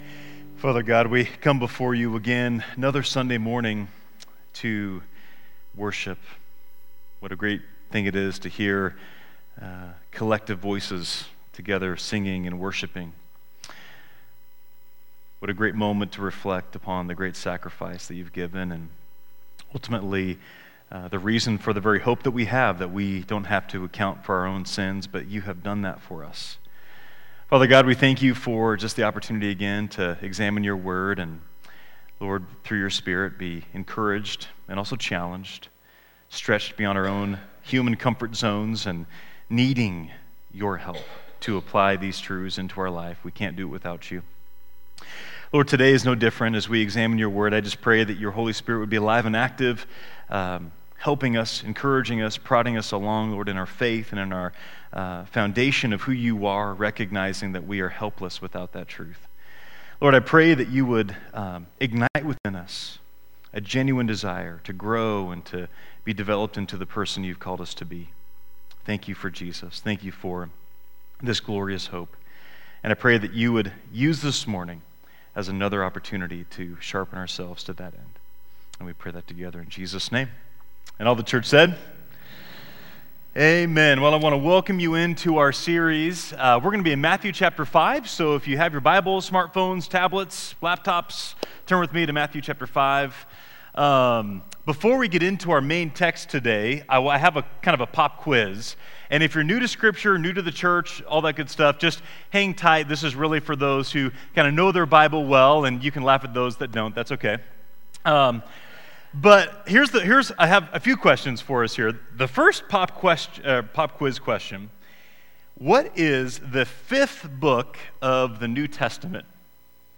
Sermons | Enterprise Christian Church